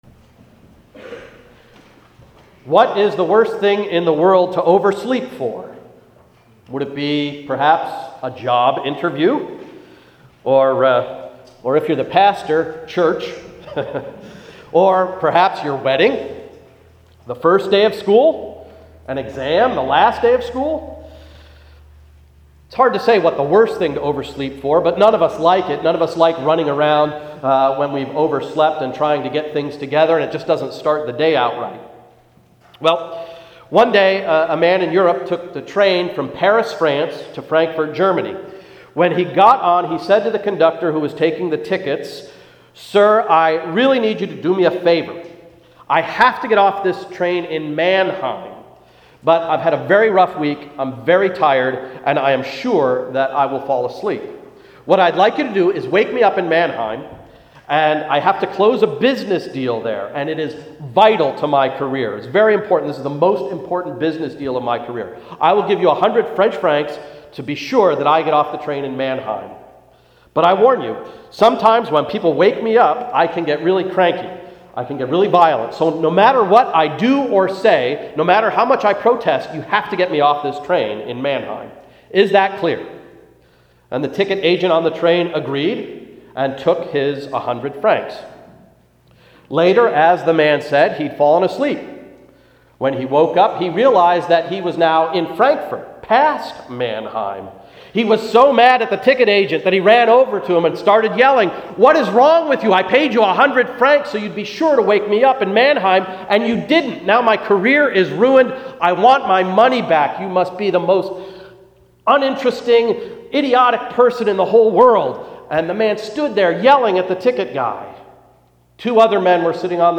Sermon of November 6, 2011